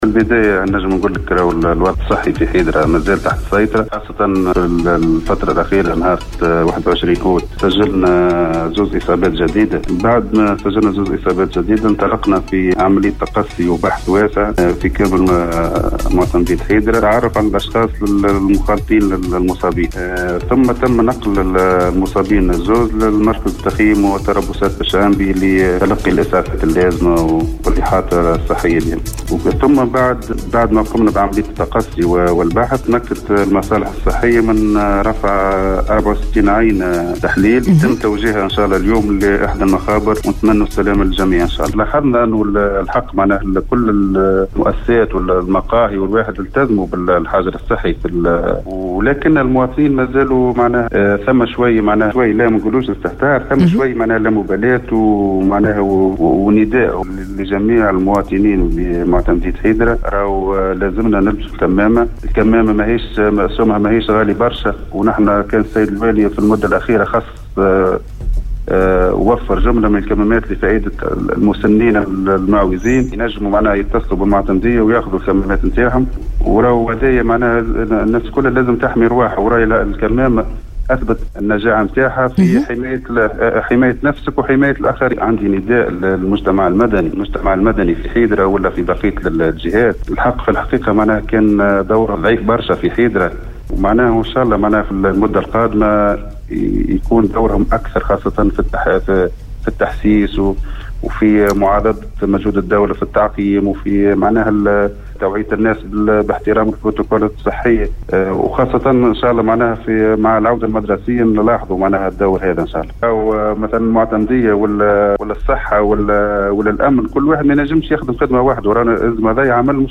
أفاد معتمد حيدرة سامي الظّوافلي في تدخّله صباح اليوم الأحد 23 أوت 2020 ببرنامج بونجور ويكاند أنّ الوضع الصحّي في حيدرة تحت السّيطرة حيث تمكنت المصالح الصحيّة من رفع 64 عينة للتحليل و توجيهها اليوم لأحد المخابر .